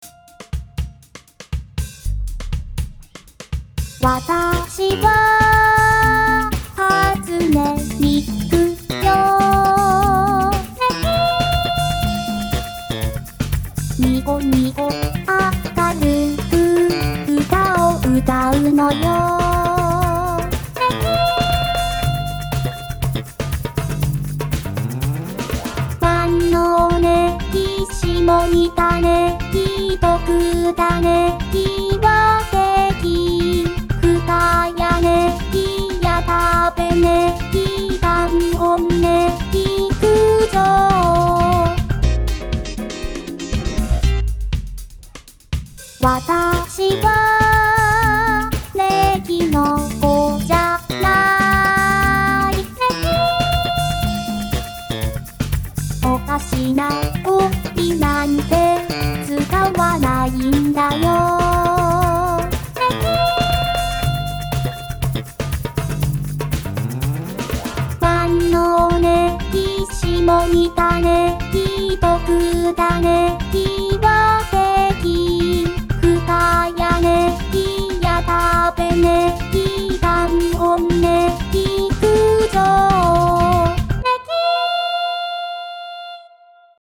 ついでなので機会が無くて張り損ねていた「ねぎっ娘と呼ばないで」もMP3置いておく。Wiiリモコンでネギ振り動画のBGMね。